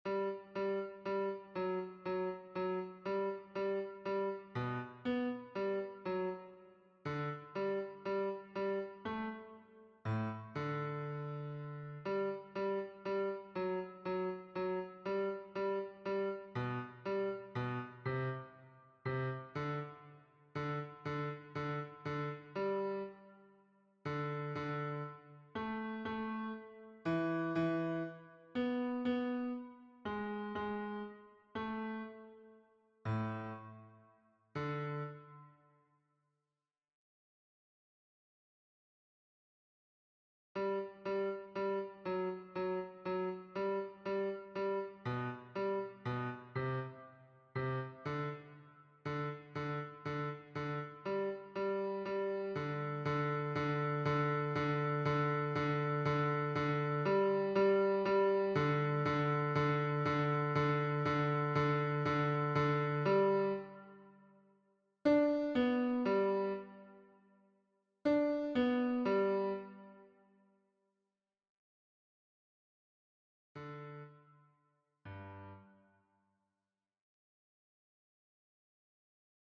MP3 version piano
Basse